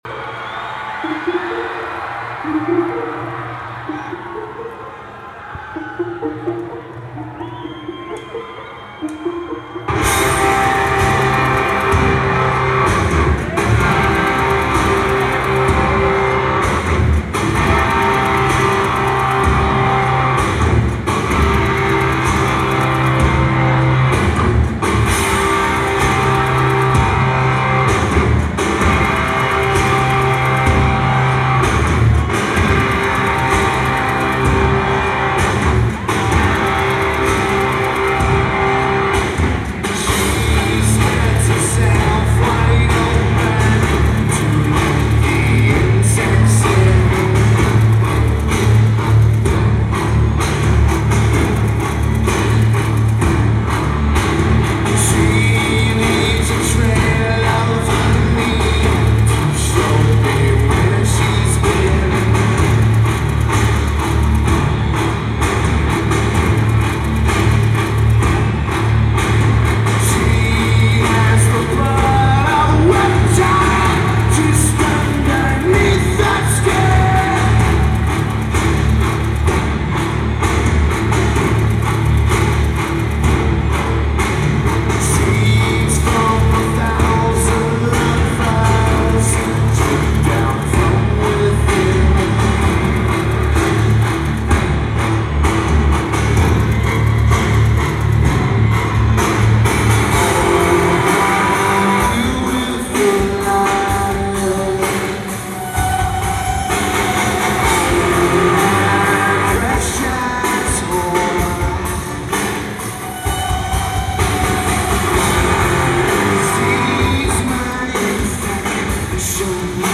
Cox Arena
Lineage: Audio - AUD (CSBs + BB + Sharp MD-MT161)